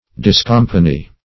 Search Result for " discompany" : The Collaborative International Dictionary of English v.0.48: Discompany \Dis*com"pa*ny\, v. t. To free from company; to dissociate.